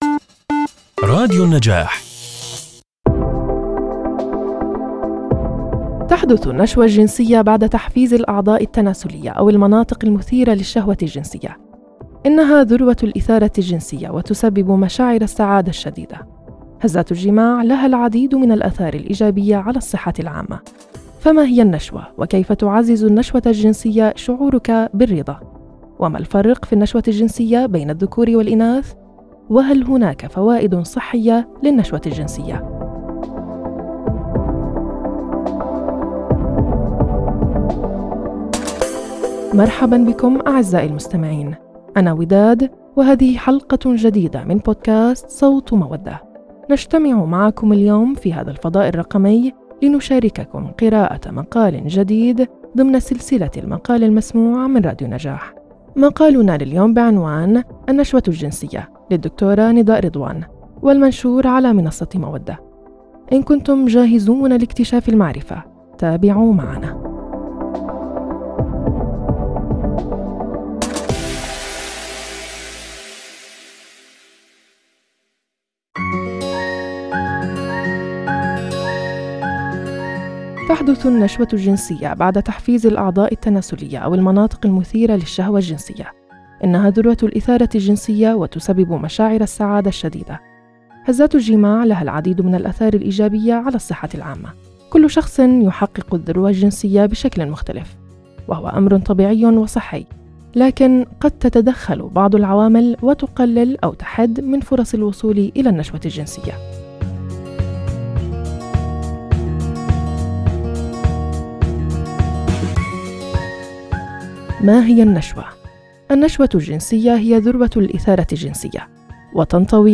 “صوت مودة” هو بودكاست فريد من نوعه ضمن سلسلة “المقال المسموع” التي يقدمها راديو النجاح.